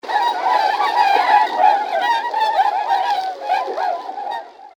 Today I heard some very familiar trumpet like calls “klooo . . . kwooo . . .” and I knew it was only a matter of time till these guys started pushing through the area (my first for 2008 – CHECK)!